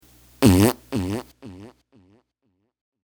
Play, download and share ToadShit Fart original sound button!!!!
toadshit-fart.mp3